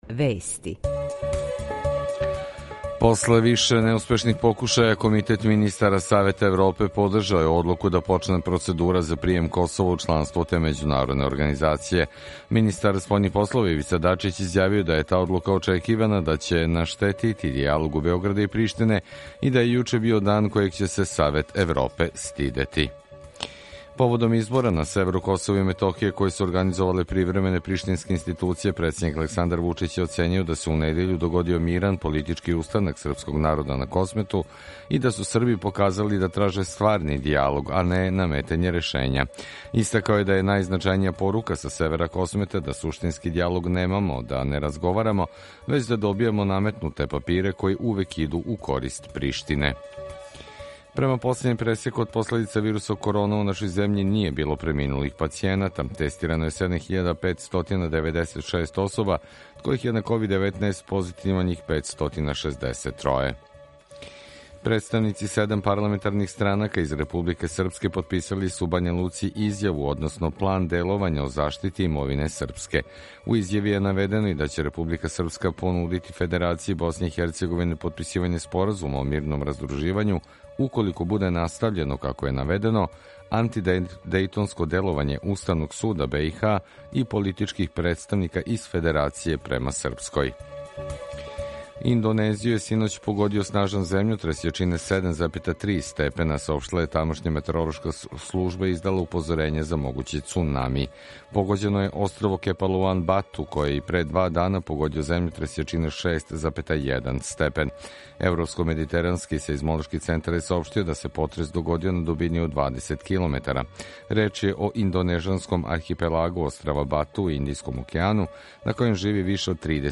Емисију реализујемо уживо из четири града, четири студија – Радио Републике Српске у Бањалуци, Радио Нови Сад, Радио Београд 2 и дописништво Радио Београда 2 у Нишу.
У два сата, ту је и добра музика, другачија у односу на остале радио-станице.